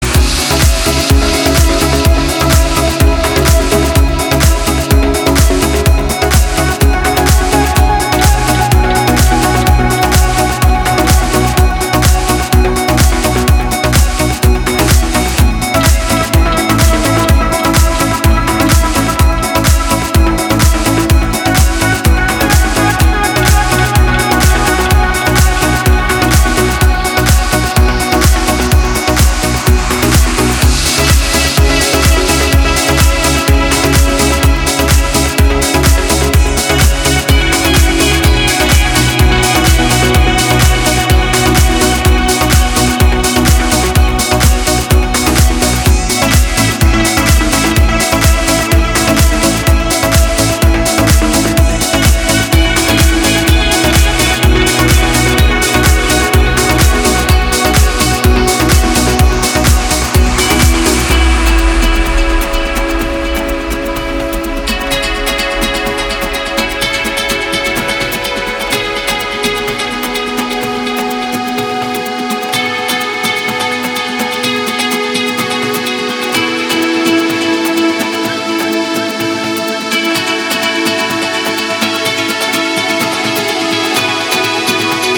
• Качество: 320, Stereo
Electronic
EDM
без слов
progressive house
Trance
Приятная летняя музыка